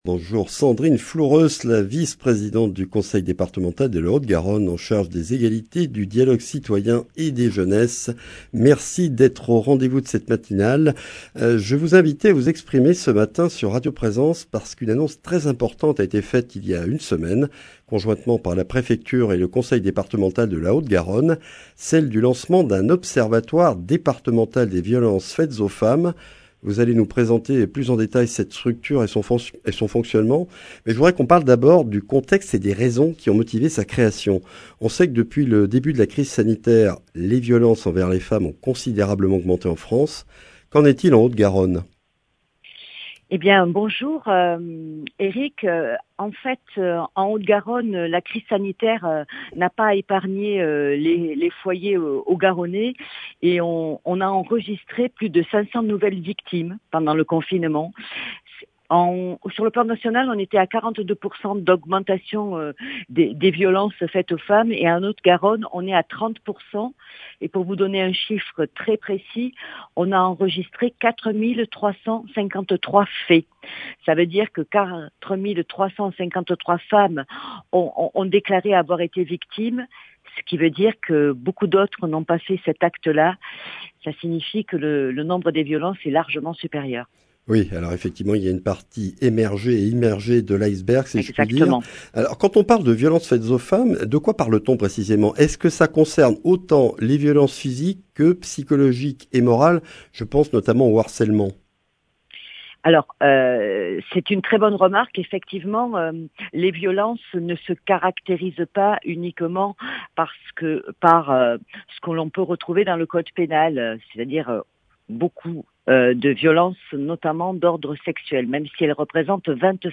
La Préfecture et le Conseil départemental de la Haute-Garonne ont annoncé conjointement le lancement de l’Observatoire départemental des violences faites aux femmes. Sandrine Floureusses, vice-présidente du Conseil départemental de la Haute-Garonne en charge des Égalités, du Dialogue citoyen et des Jeunesses, présente les missions de cette nouvelle structure, créée dans un contexte de recrudescence des violences intra-familiales depuis le début de la crise sanitaire.
Accueil \ Emissions \ Information \ Régionale \ Le grand entretien \ Création de l’Observatoire départemental des violences faites aux femmes de (…)